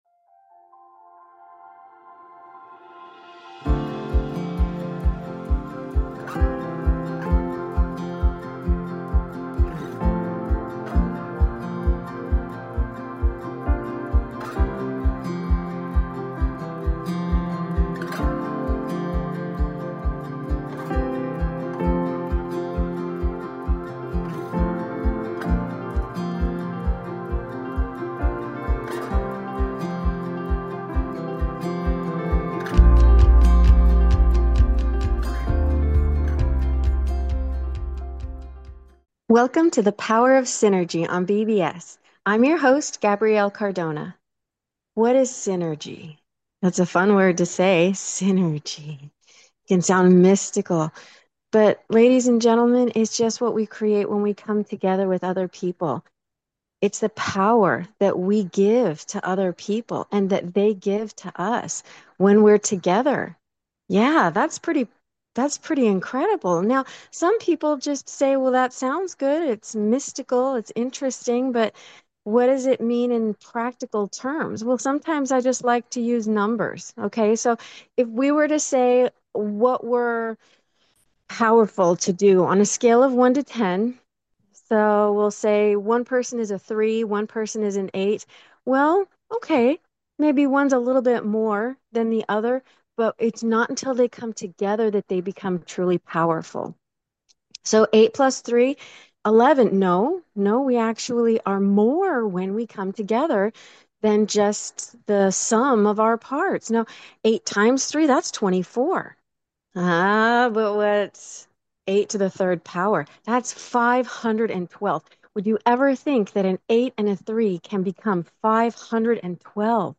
The Power of Synergy Talk Show